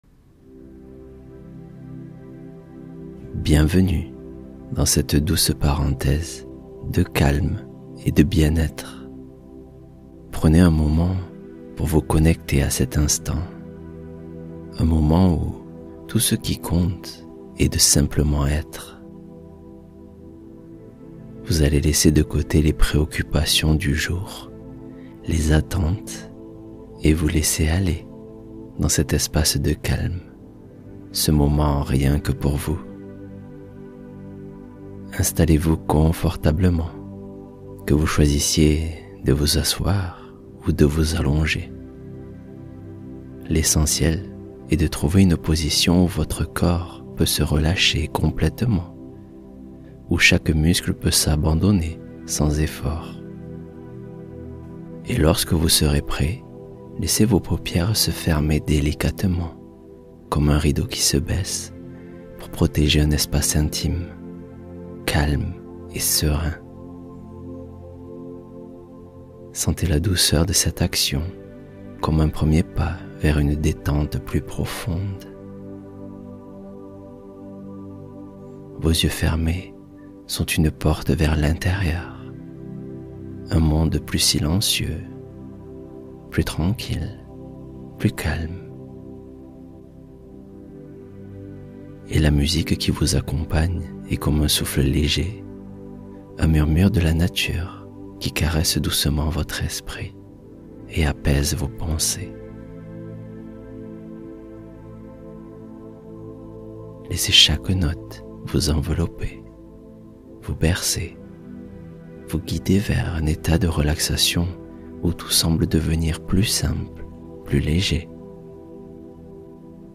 Se reconnecter à sa valeur intérieure — Méditation guidée d’estime de soi